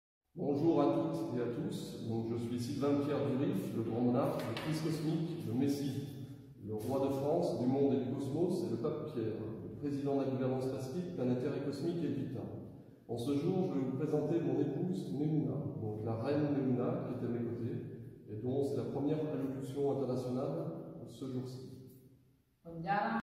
Allocution royale et papale messianique sound effects free download